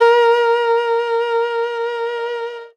52-bi07-erhu-f-a#3.wav